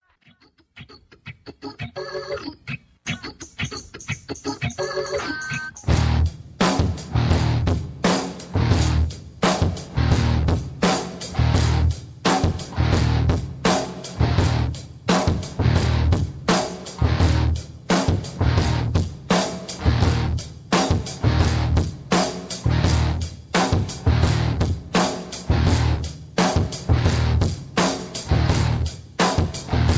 karaoke, mp3 backing tracks
rock, hip hop, rap, beat tracks